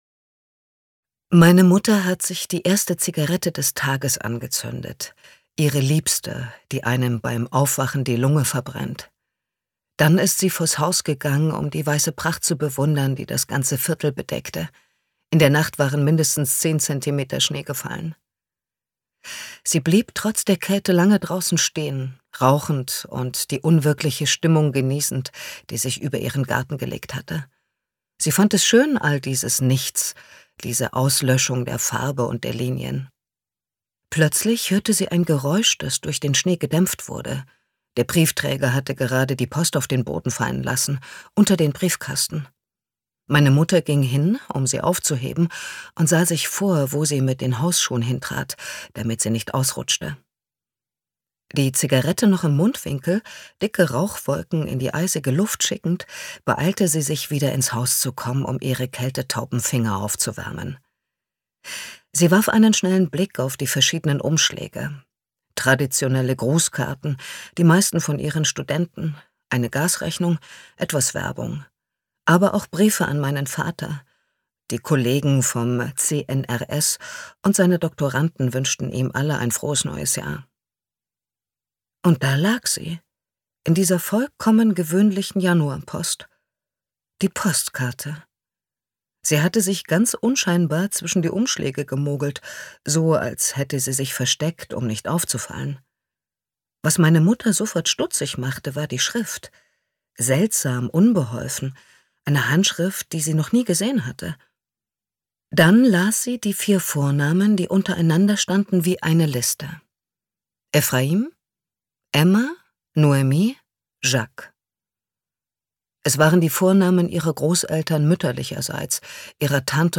Ungekürzte Ausgabe OSTERWOLDaudio